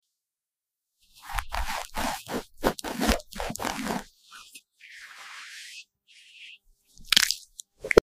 Dragon Fruit ASMR | PT sound effects free download